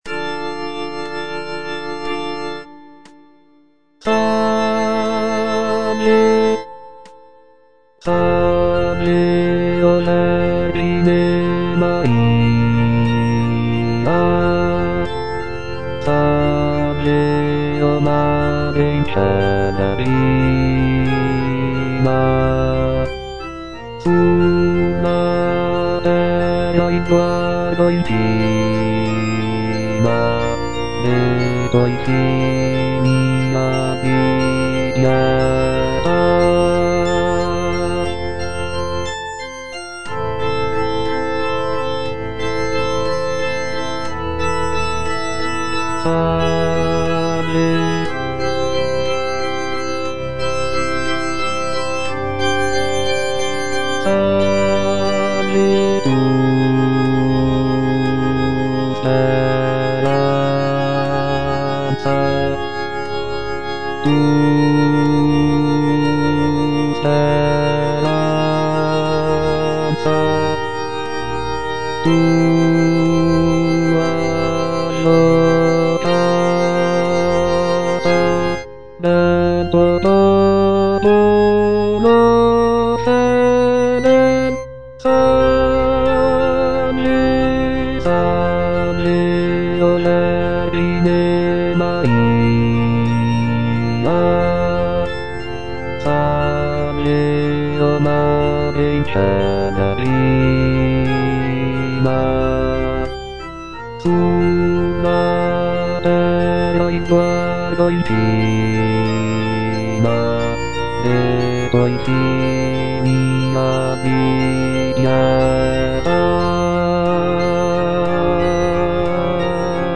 G. ROSSINI - SALVE O VERGINE MARIA Bass (Voice with metronome) Ads stop: auto-stop
"Salve o vergine Maria" is a choral piece composed by Gioachino Rossini in 1831.
The music is characterized by its serene and devotional atmosphere, with lush harmonies and expressive melodies.